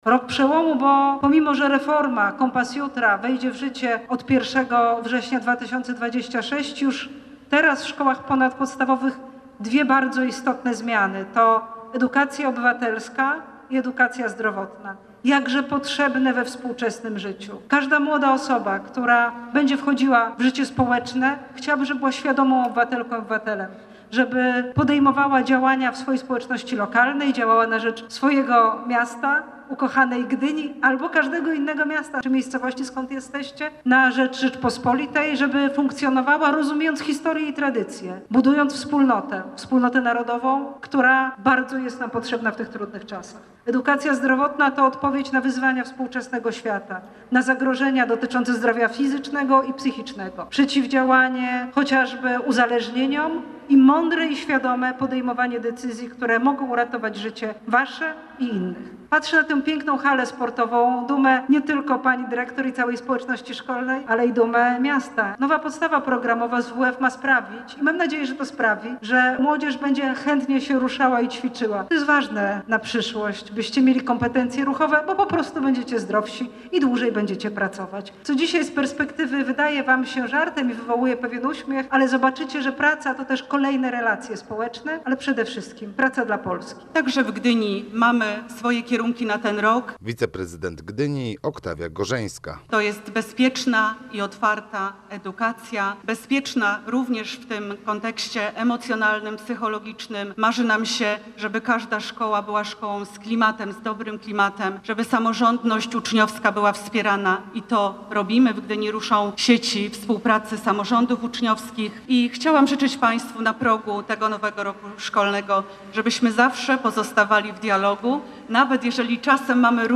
Minister edukacji narodowej Barbara Nowacka wzięła udział w ogólnopolskim rozpoczęciu roku szkolnego 2025/2026 w Zespole Szkół Hotelarsko-Gastronomicznych w Gdyni. Jak poinformowała, resort edukacji przewidział na ten rok kilka niezbędnych zmian dla szkoły i przyszłości.